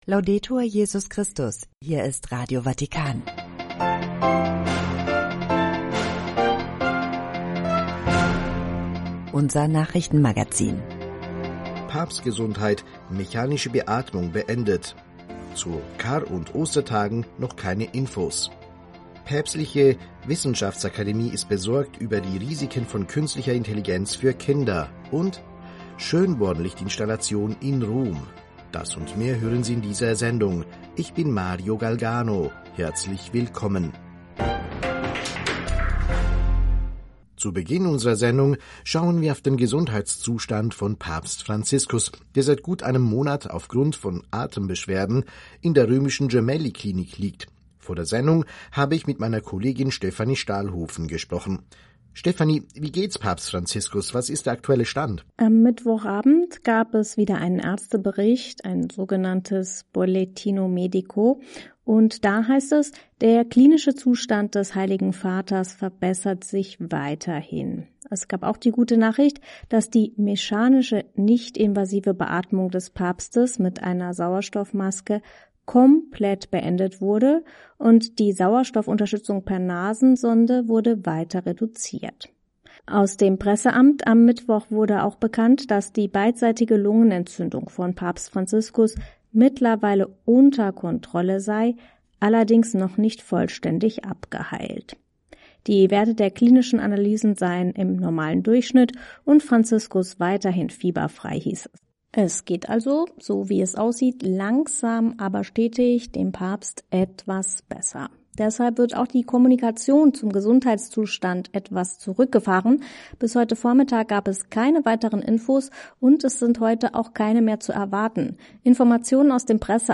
ไม่ต้องลงทะเบียนหรือติดตั้ง Treffpunkt Weltkirche - Nachrichtenmagazin (18 Uhr).